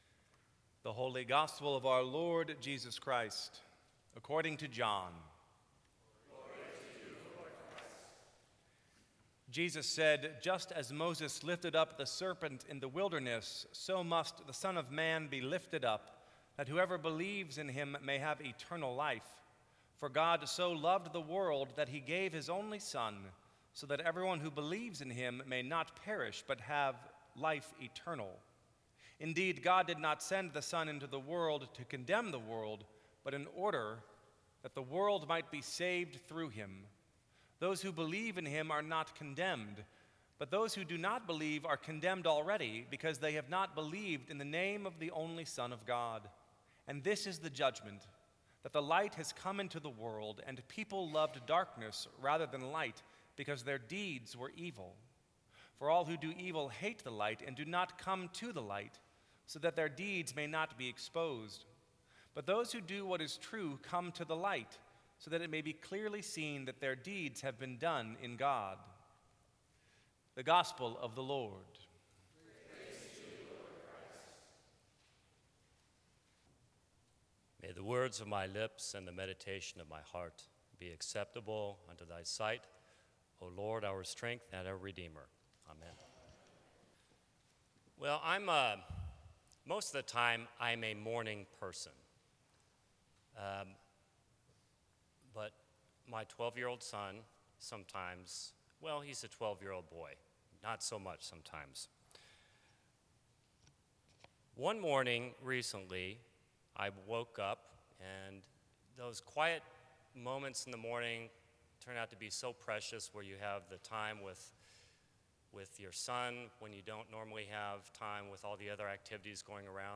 Sermons from St. Cross Episcopal Church Atonement and Giant Corn Dogs Sep 25 2015 | 00:12:47 1x 00:00 / 00:12:47 Subscribe Share Apple Podcasts Spotify Overcast RSS Feed Share Link Embed